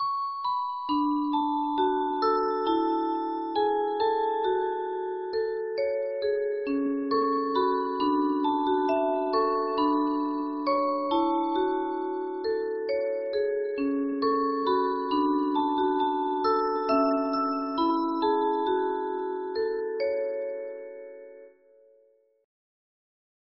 mechanical music boxes